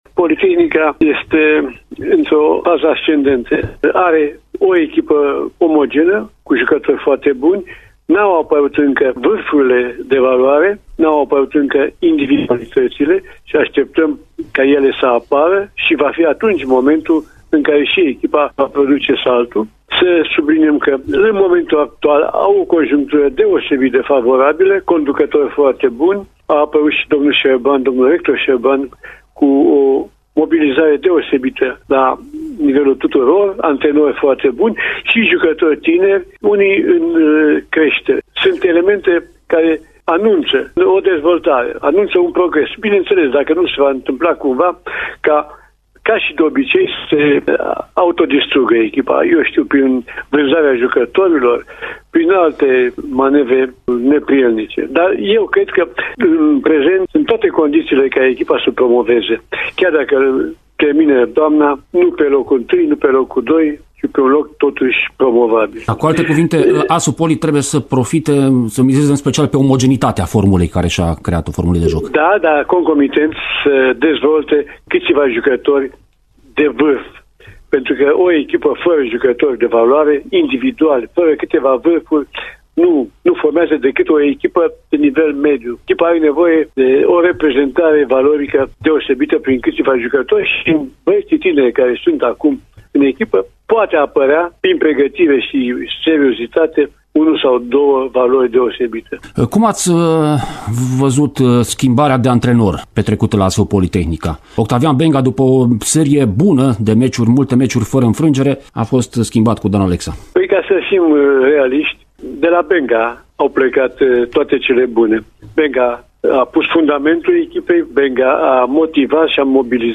a vorbit ieri, la Radio Timișoara, despre echipa suporterilor.